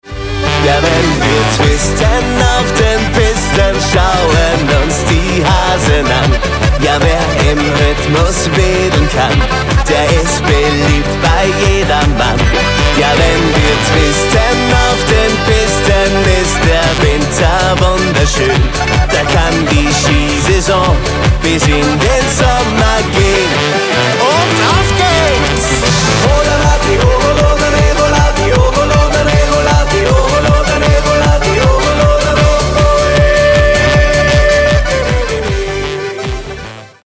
Genre: Party Schlager